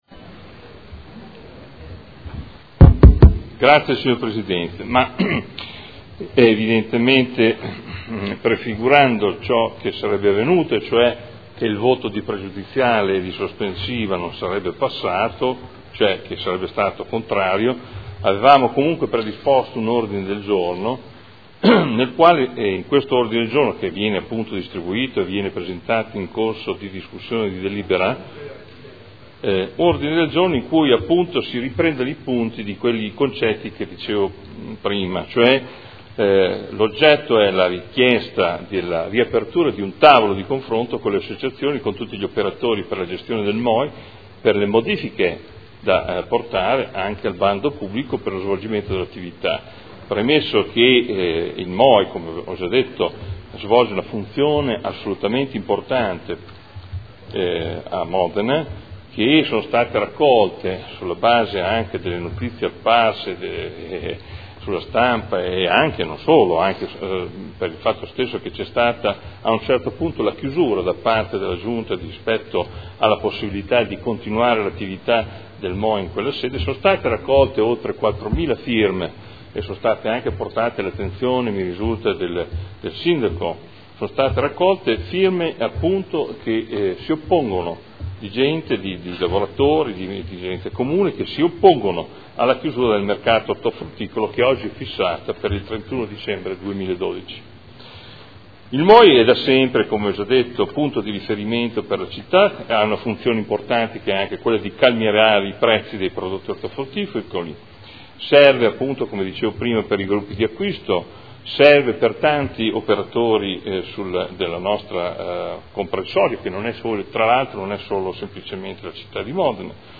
Seduta del 18/06/2012. Introduce nuovo Ordine del Giorno